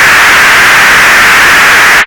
RADIOFX  8-R.wav